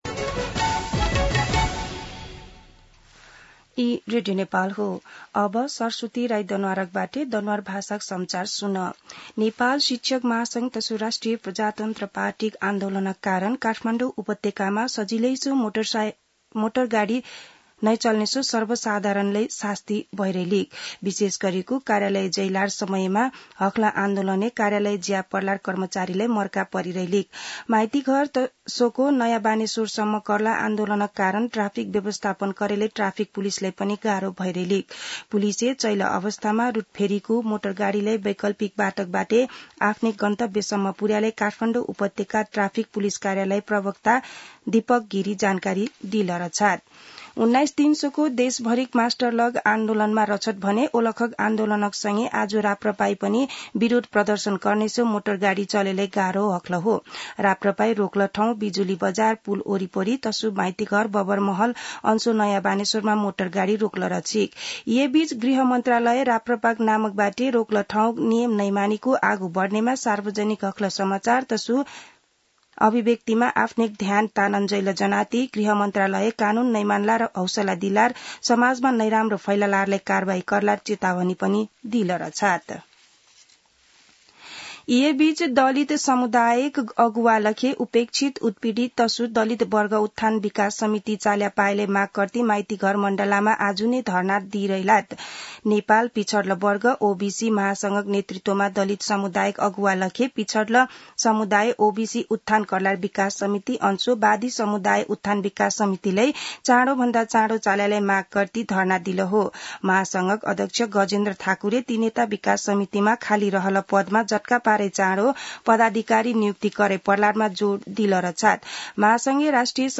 दनुवार भाषामा समाचार : ७ वैशाख , २०८२
Danuwar-News-10.mp3